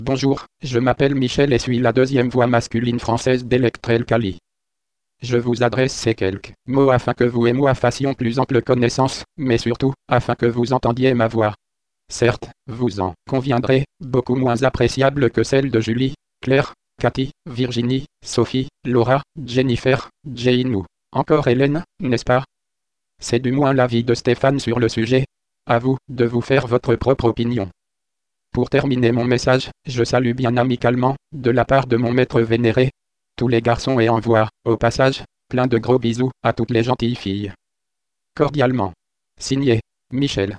Texte de démonstration lu par Michel, deuxième voix masculine française d'Electrel Kali (Version 3.17)